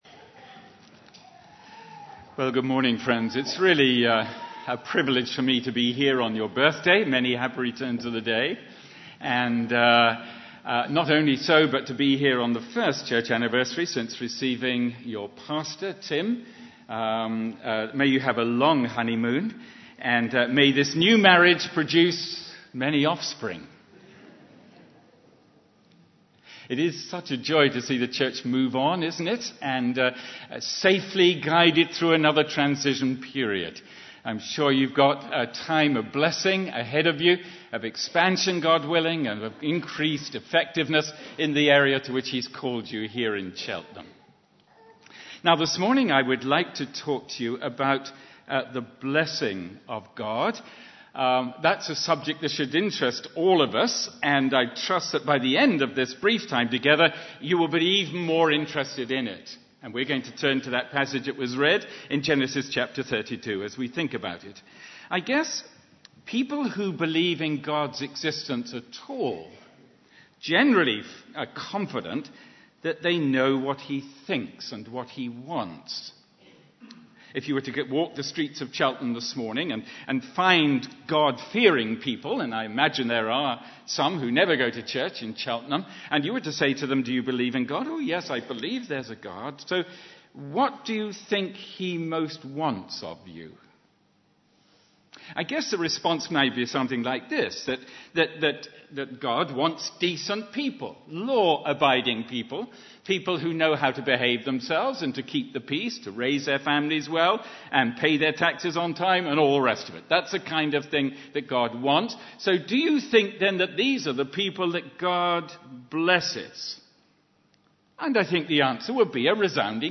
Guest speaker for the 166th Church Anniversary
2009 Service type: Sunday AM Bible Text